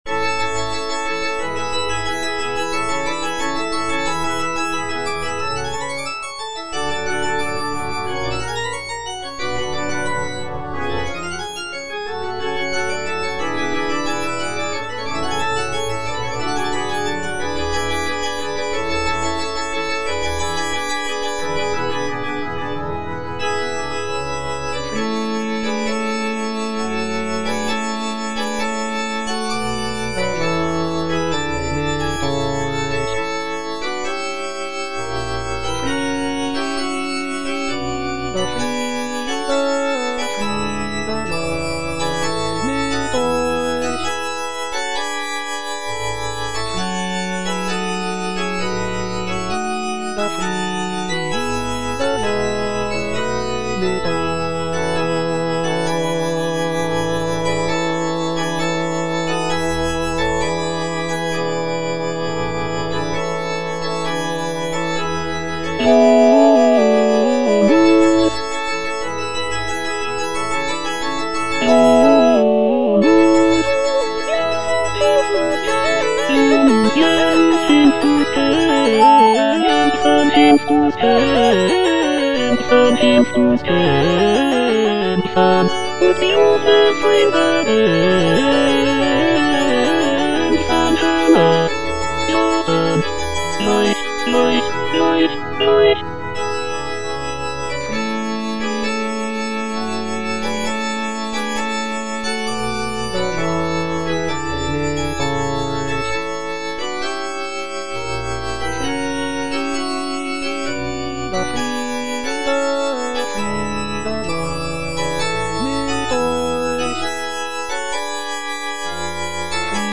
Choralplayer playing Cantata
The cantata is known for its intricate vocal and instrumental writing, as well as its rich harmonies and expressive melodies.